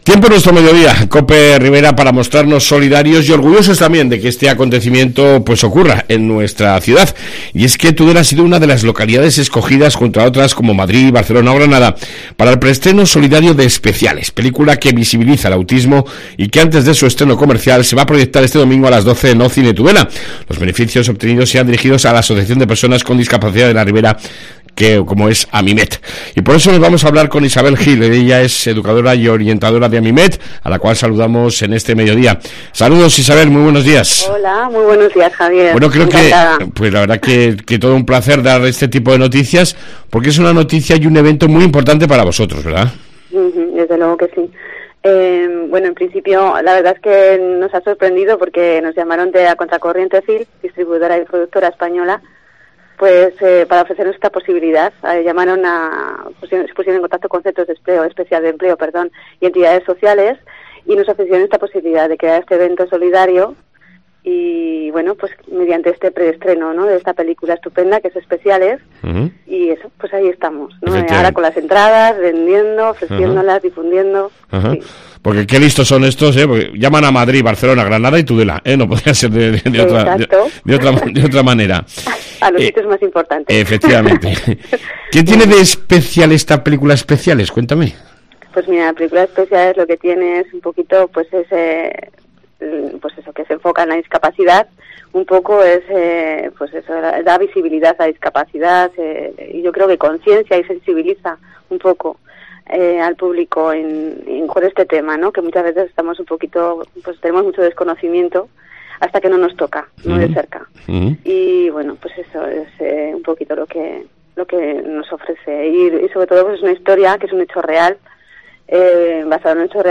AUDIO: Entrevista con AMIMET ante el estreno en Tudela de la película "ESPECIALES"